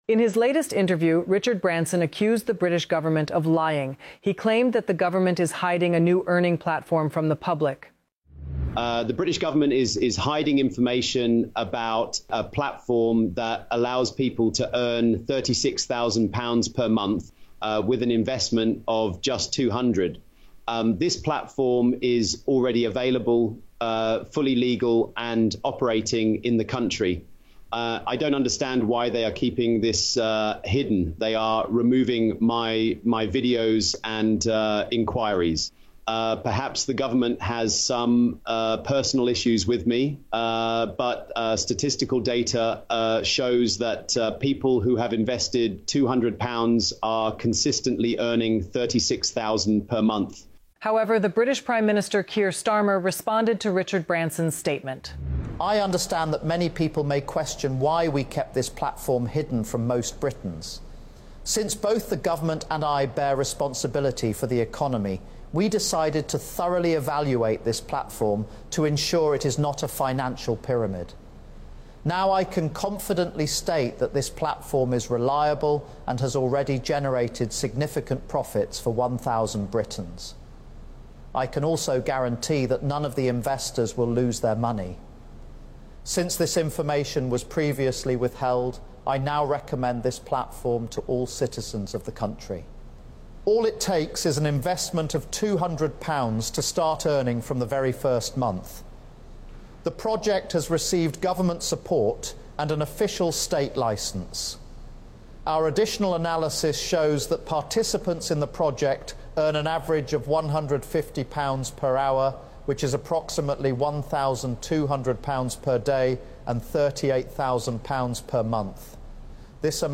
This is AI created content, which they have used to clone their exact voices and then adapt to video content. Please be aware of anyone endorsing crypto investments.